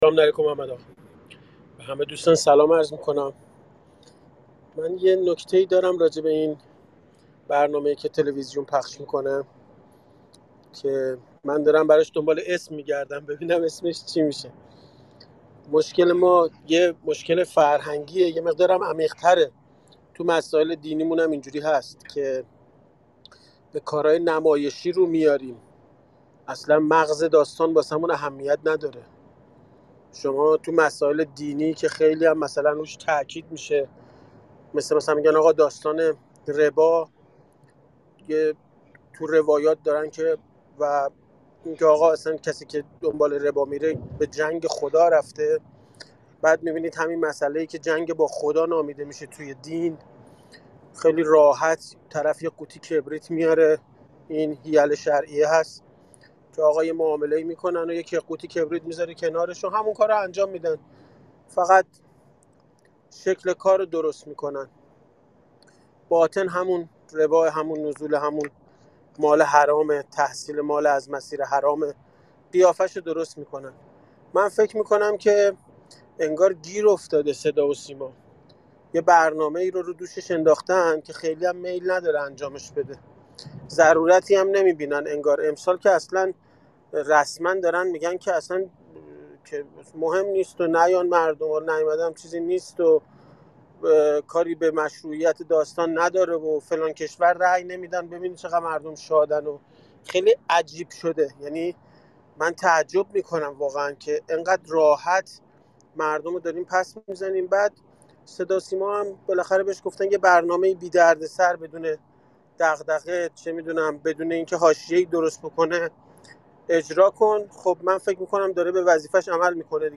حسن آقامیری در کلاب هاوس درباره مناظره تلویزیونی انتخابات ریاست جمهوری و حواشی آن صحبت کرد.